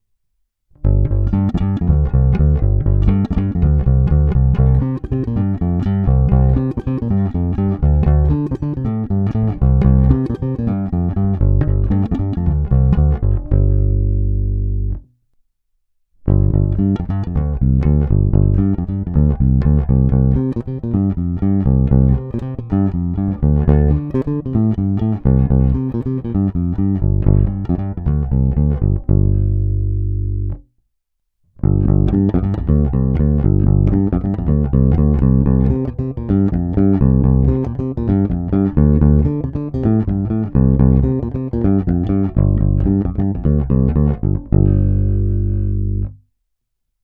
Zvuk je typický Precision.
Není-li uvedeno jinak, následující nahrávky jsou vyvedeny rovnou do zvukové karty, vždy s plně otevřenou tónovou clonou, a kromě normalizace ponechány bez úprav.